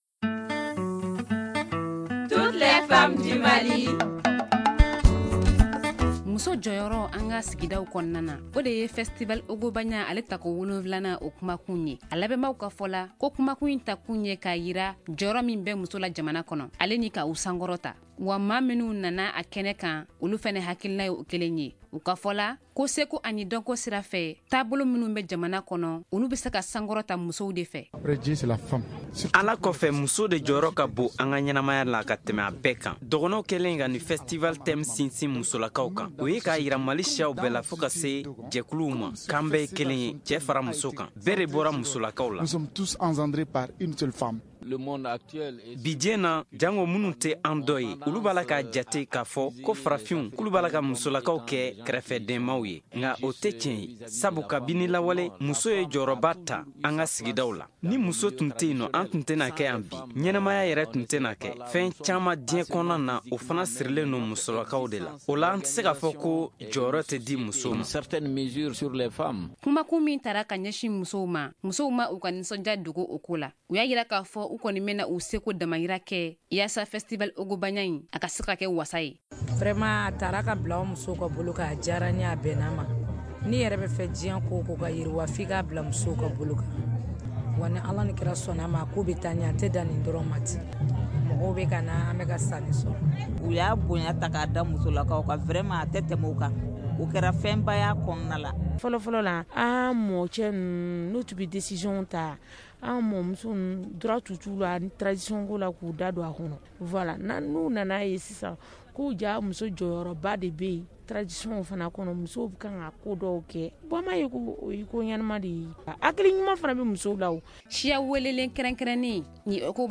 Le magazine « Toutes les femmes du Mali » d’aujourd’hui, nous amène au festival « Ogobagna ».
« La place de la femme dans nos sociétés traditionnelles » est le thème de cette édition. Des participantes saluent le choix de ce thème.